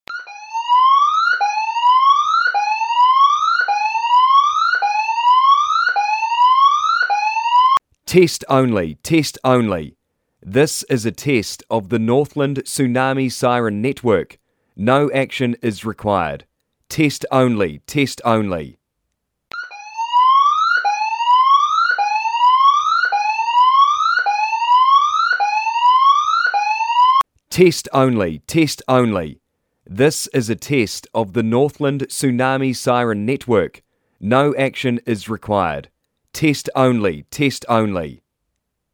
Tsunami Siren Test Only
tsunami-siren-test-only-x2.mp3